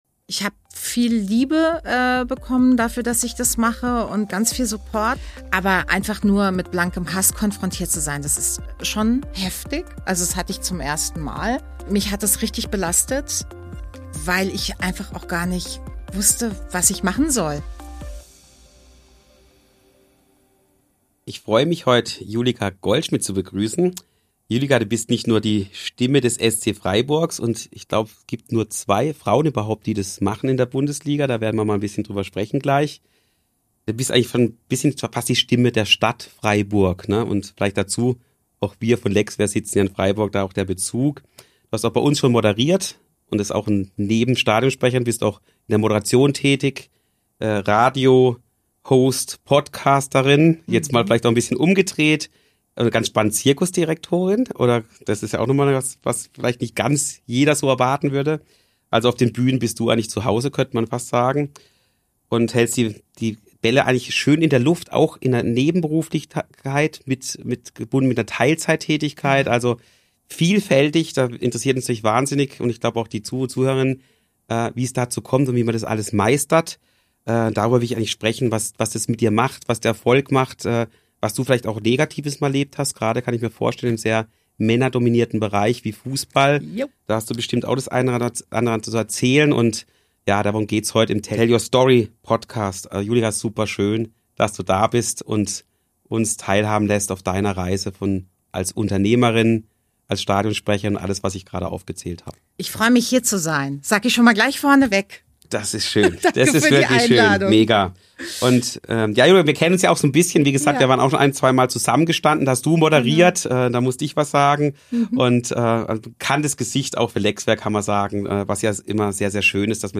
Im Gespräch erzählt sie von ihrer Arbeit in männerdominierten Bereichen wie dem Fußballgeschäft sowie ihrem Umgang mit Kritik auf Social Media-Plattformen.